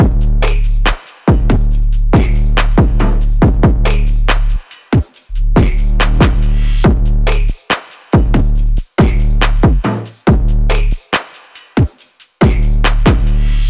• Busted Phonk Type Drums - 140 C.ogg
Hard punchy kick sample for Memphis Phonk/ Hip Hop and Trap like sound.